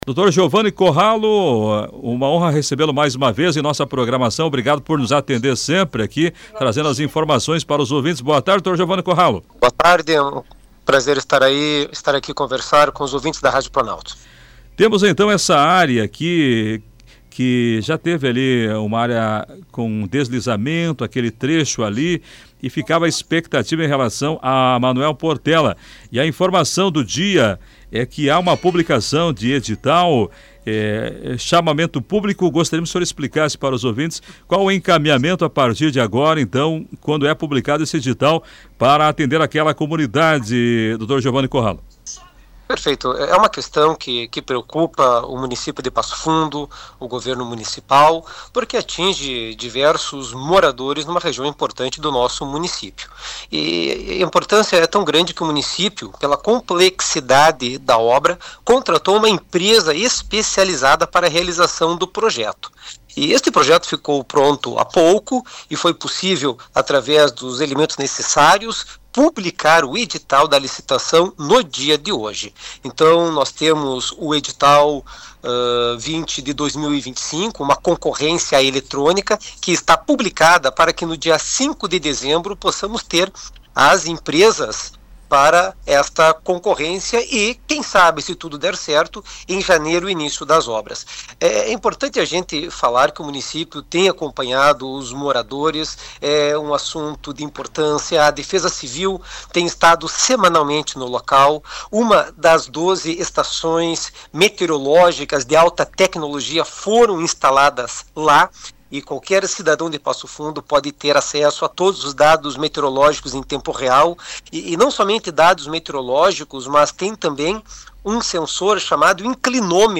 Nesta quarta-feira, 19, foi publicado o edital com o Chamamento Público para a realização de obra de contenção. A confirmação foi dada em entrevista à Rádio Planalto News (92.1) pelo Procurador-Geral do Município, Giovani Corralo.